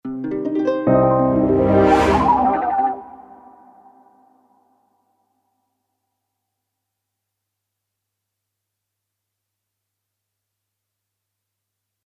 Hier verbirgt sich eine der kürzesten akustischen Reisen entlang der Musikgeschichte analog zu den dargestellten Motive: Mandoline, Pianoforte, Celesta, Crescendo eines Sinfonieorchesters und moderner mehrfach gefilterter Softwaresynthesizersequenz:
Logo_2-cut-ohne-Git-90-bpm-hinten-leiser.mp3